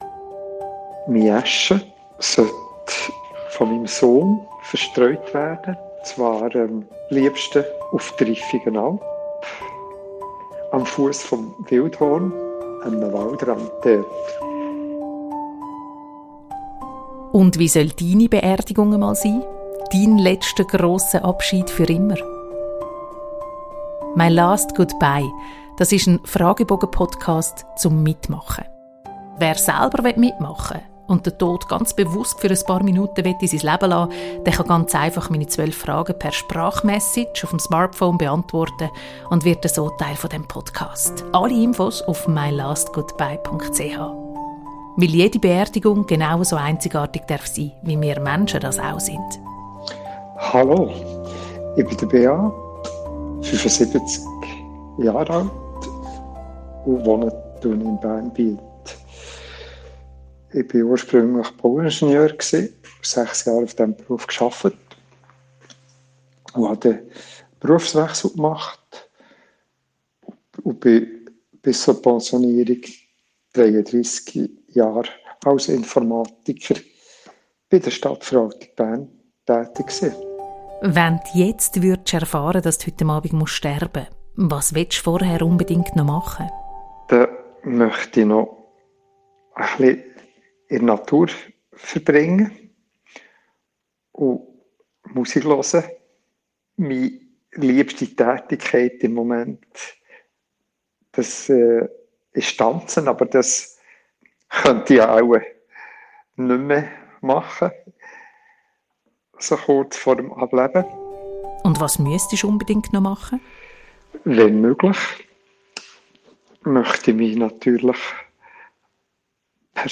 die diesmal etwas lausige Whatsapp-Audioqualität.